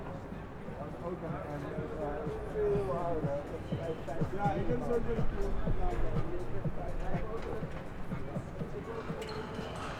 Environmental
UrbanSounds
Streetsounds
Noisepollution